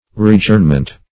Search Result for " readjournment" : The Collaborative International Dictionary of English v.0.48: Readjournment \Re`ad*journ"ment\ (r[=e]`[a^]d*j[^u]rn"ment), n. The act of readjourning; a second or repeated adjournment.